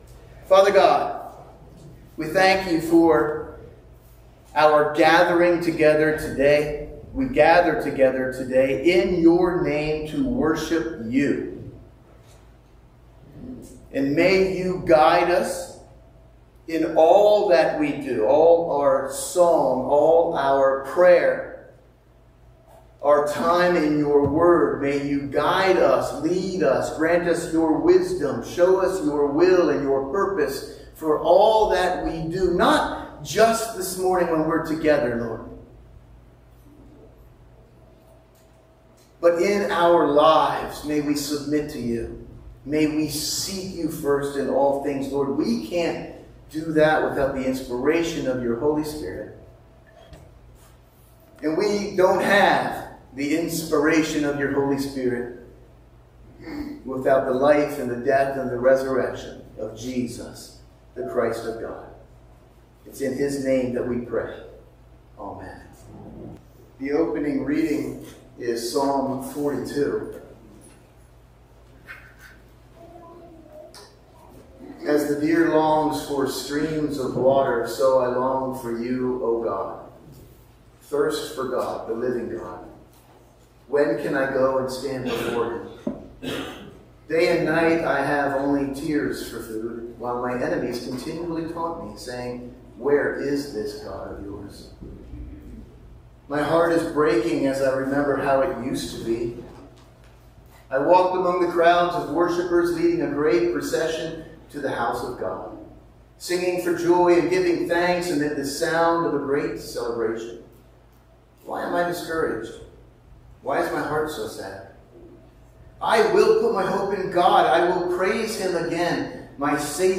Sunday Morning Service – July 7, 2024